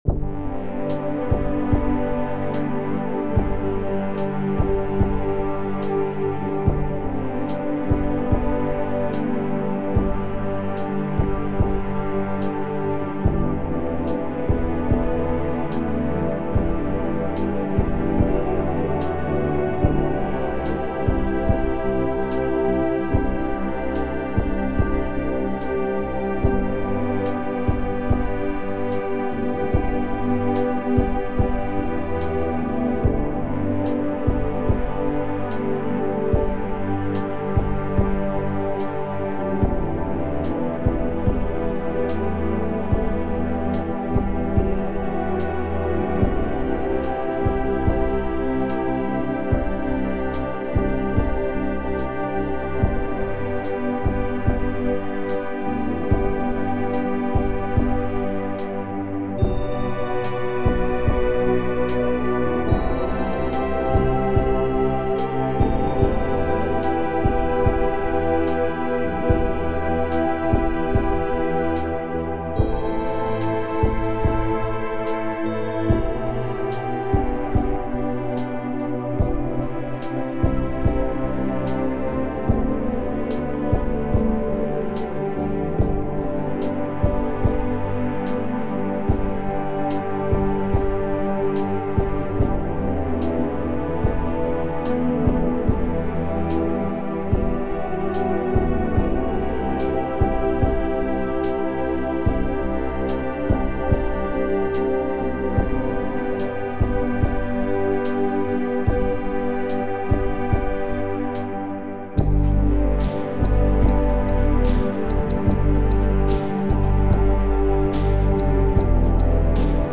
Ableton's Electric instrument. The bass patch had a built-in triplet that let into playing 3 against 4.